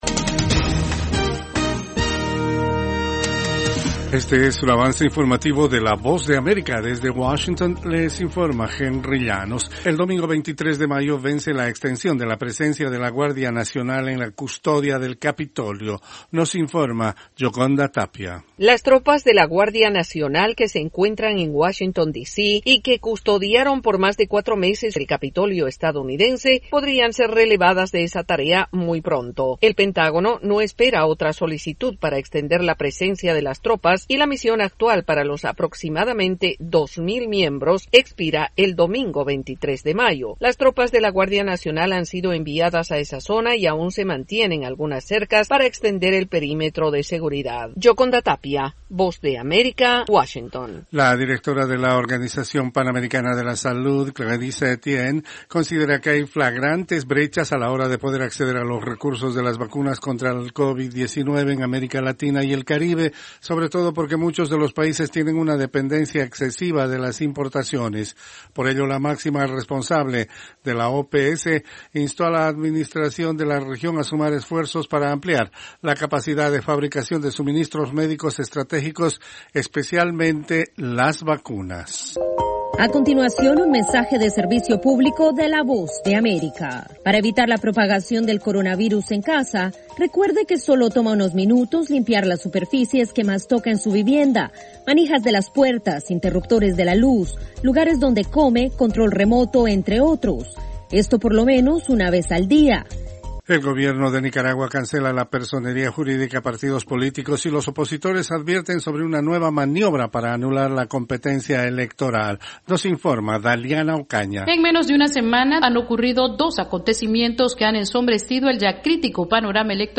Cápsula informativa de tres minutos con el acontecer noticioso de Estados Unidos y el mundo. [12:00pm Hora de Washington].